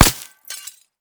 glass04hl.ogg